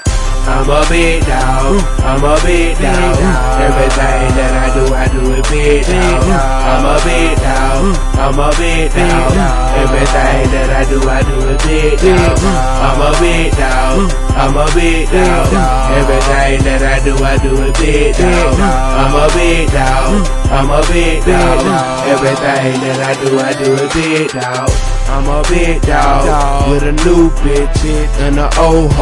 Hip Hop
Rap